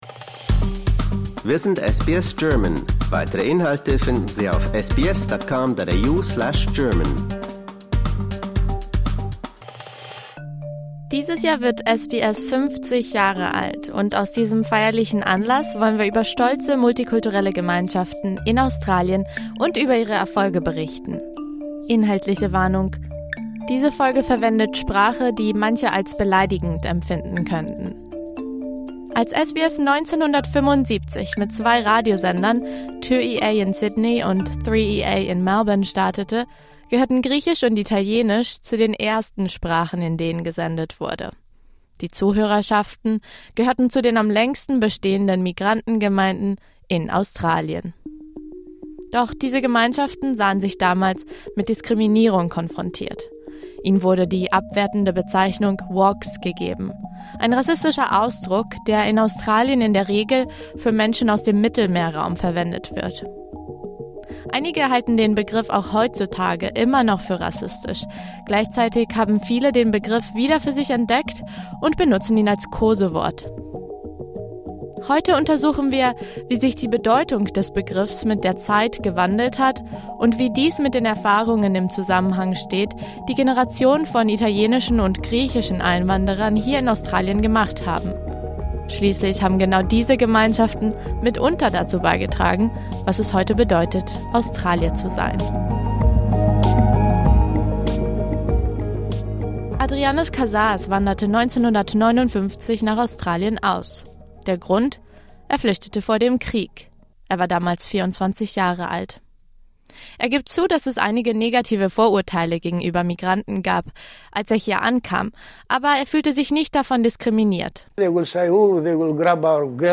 Diese griechischen und italienischen Migranten erzählen von ihren Erfahrungen in Australien und von ihrem Platz in diesem Land.